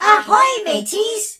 darryl_start_vo_04.ogg